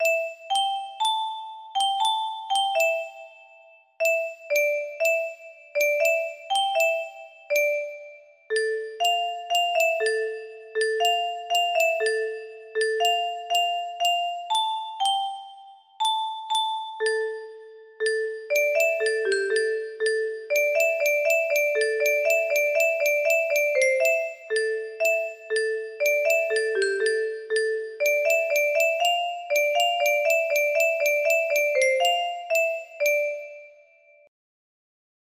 GDDDDDDDDDDDDDD music box melody
Grand Illusions 30 (F scale)